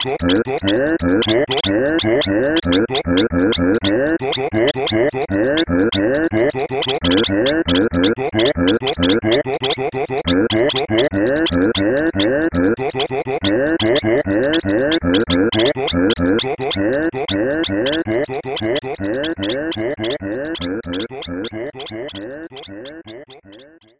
Voice clip from Tetris & Dr. Mario
T&DM_Virus_Voice_2.oga.mp3